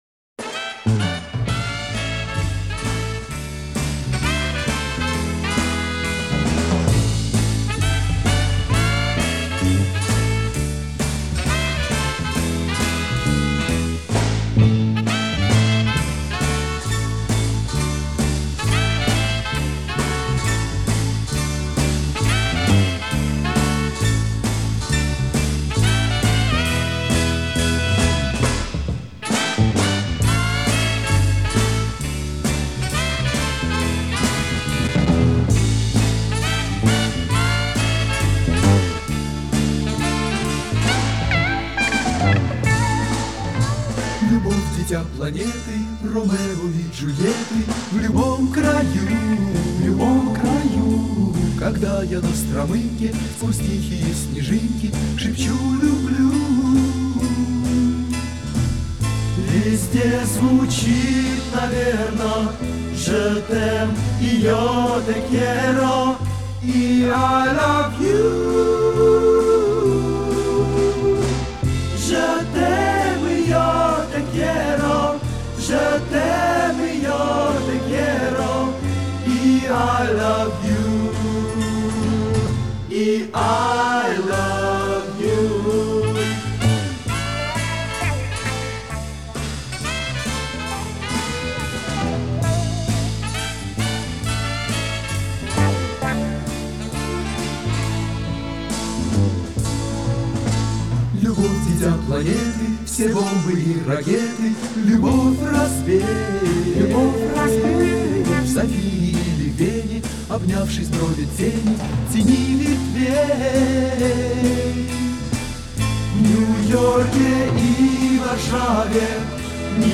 с оркестром
подпевки женские слышны в конце песни